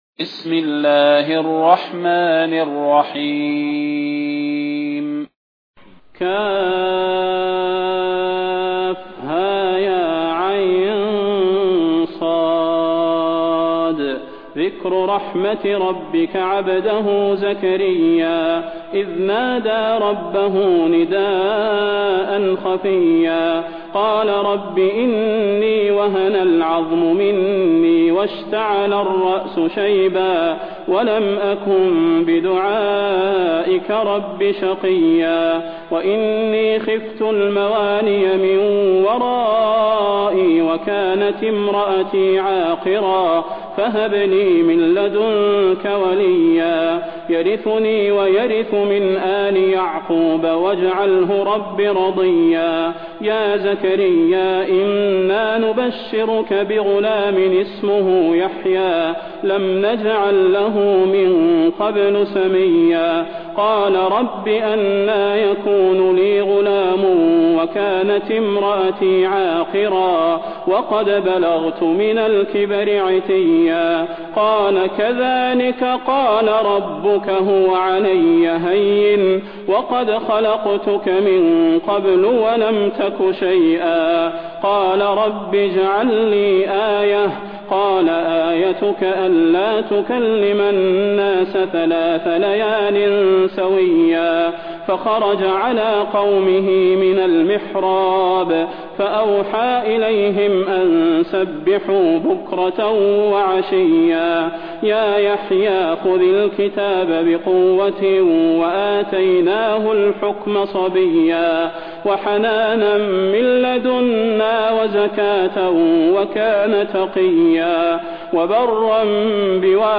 المكان: المسجد النبوي الشيخ: فضيلة الشيخ د. صلاح بن محمد البدير فضيلة الشيخ د. صلاح بن محمد البدير مريم